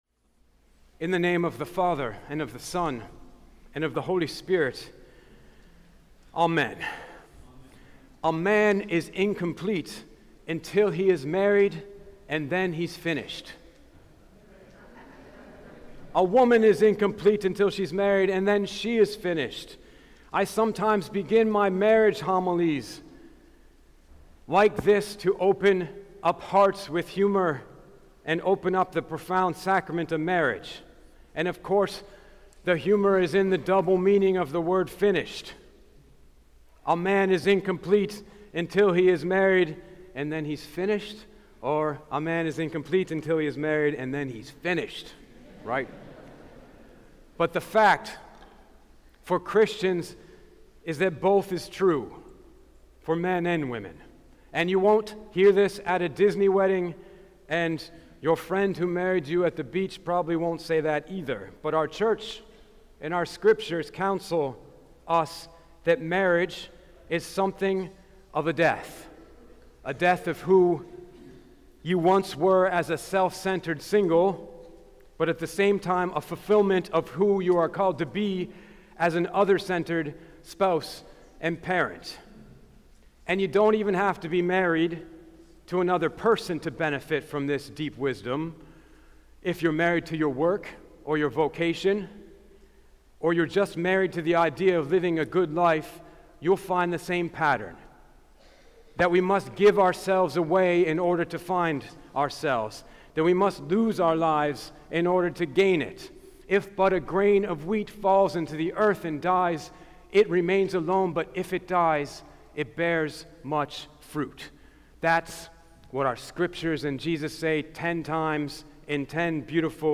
Podcasts Sermons St. Hagop Armenian Church